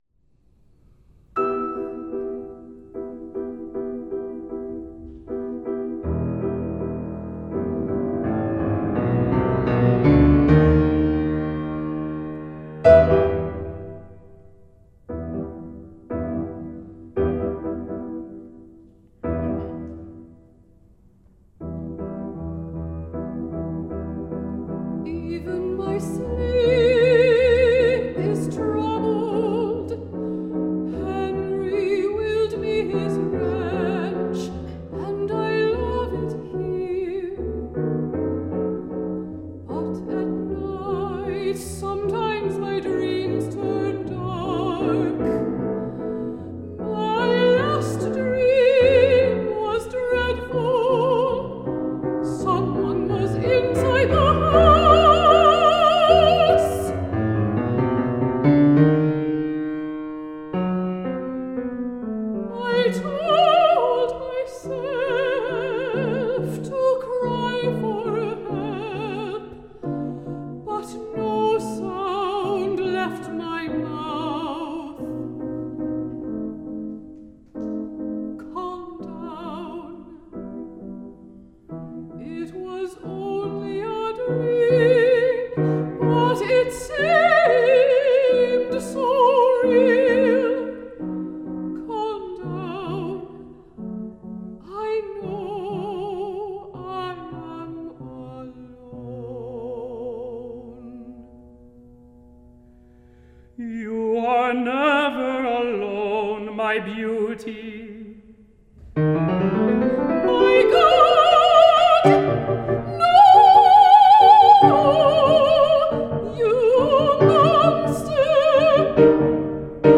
mezzo soprano
baritone
piano